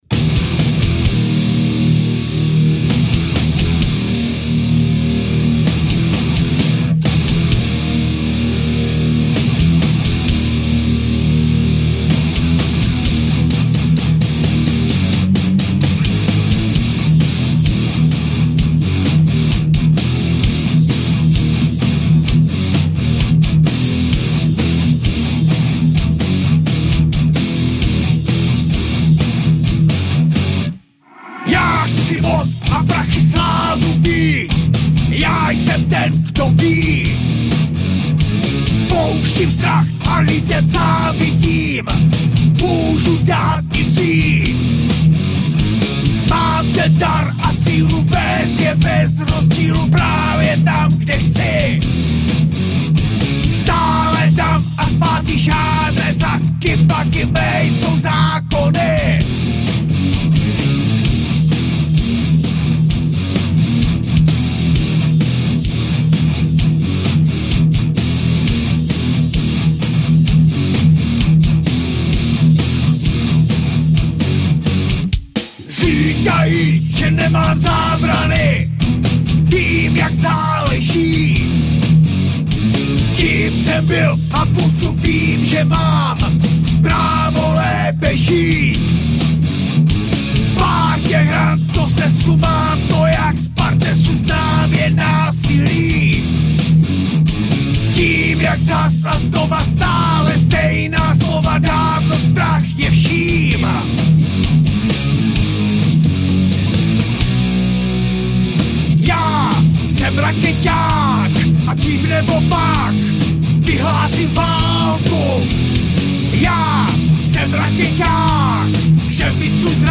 Žánr: Metal/HC